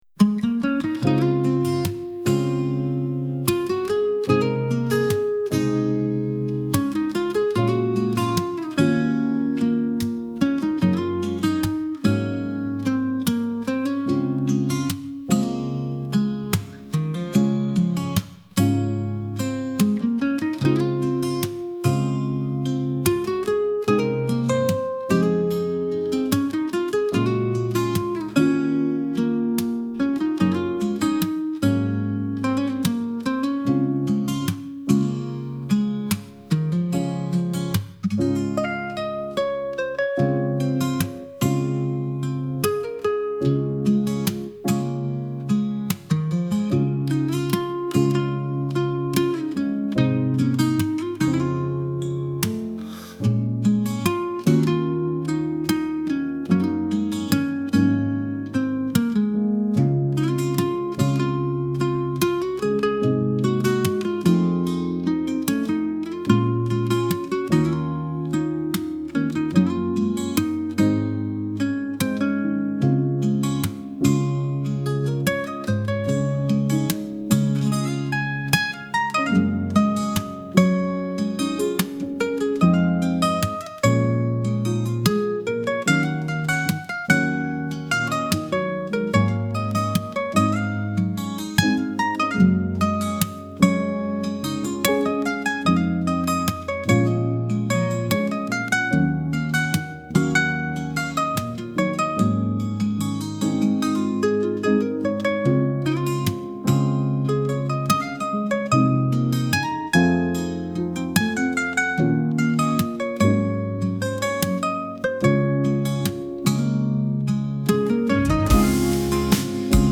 どこか懐かしい夏 バラード 懐かしい 穏やか 青春